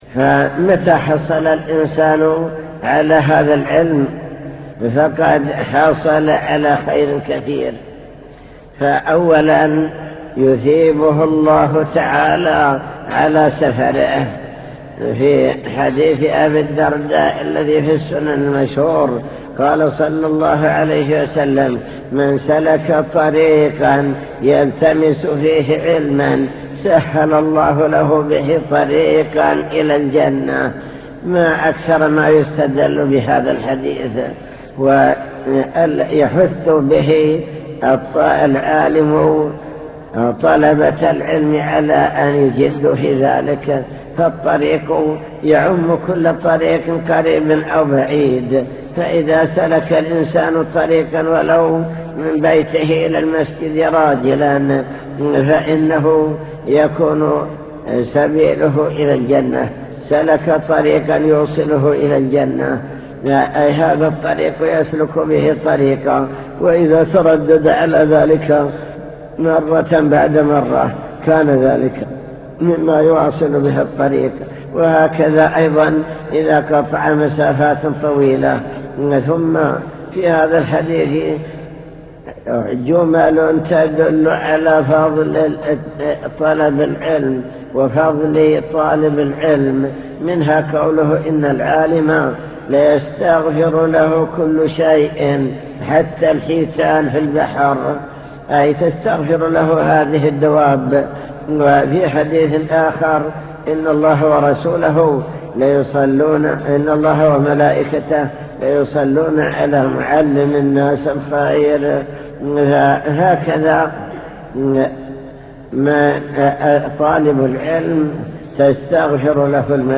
المكتبة الصوتية  تسجيلات - لقاءات  لقاء مفتوح مع الشيخ